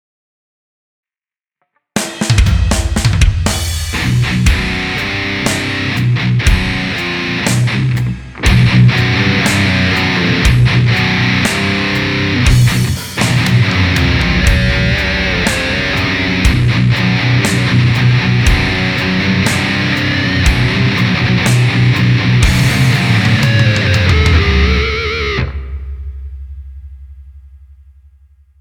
Накручиваем тяж метальный звук на гитарах!
Тут использовал софт Pod Farm-2... Звук конечно у него ужасный... какой-то пластмассово-ватный...
строй пониженный.. в СИ